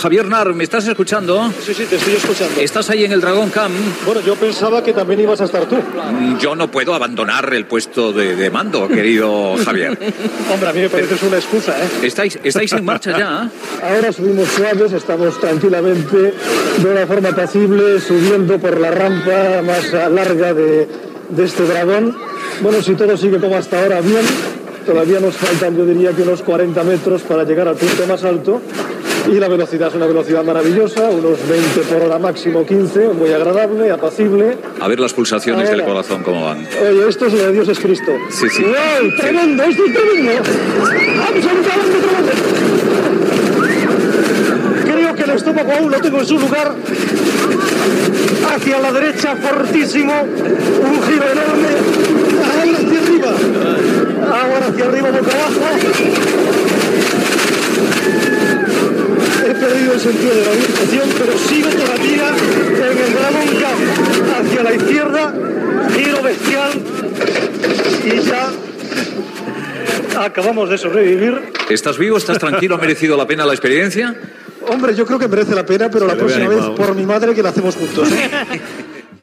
Dia de la inaguració de Port Aventura i narració des de l'atracció El Dragon Khan
Info-entreteniment